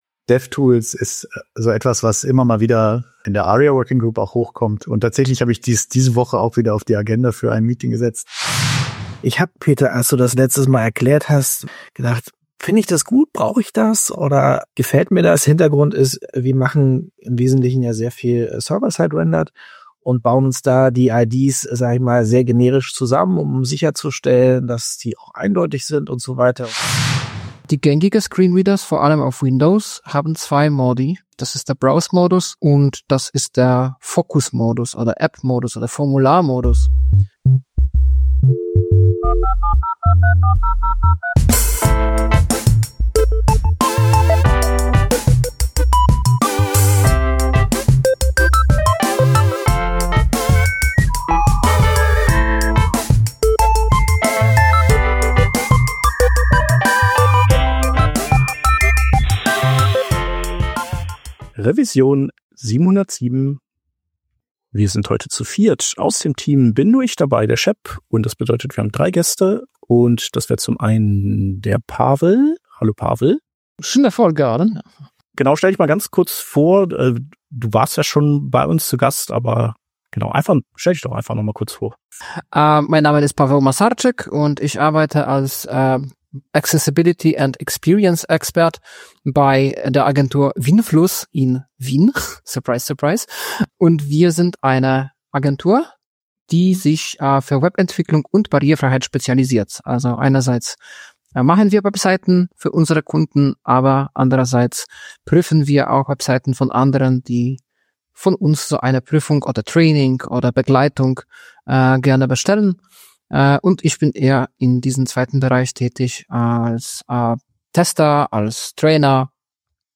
In dieser Folge sprechen wir zu zweit über unsere Eindrücke rund um den Government Site Builder (GSB) – ausgelöst durch unseren Besuch auf der T3CON in Düsseldorf.
Herausgekommen ist stattdessen eine kurze, leicht rantige Bestandsaufnahme darüber, wie schwer es ist, überhaupt belastbare Informationen oder Gesprächspartner:innen zum GSB zu finden.